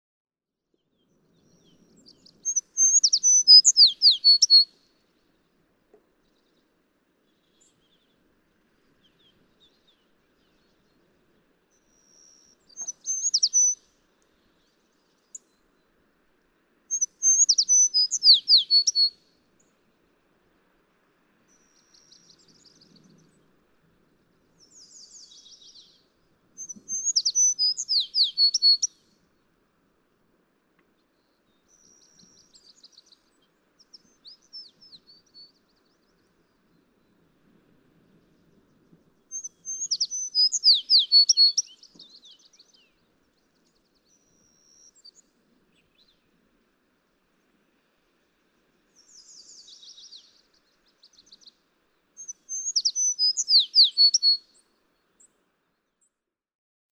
American tree sparrow
♫272, ♫273, ♫274—longer recordings from those three individuals
274_American_Tree_Sparrow.mp3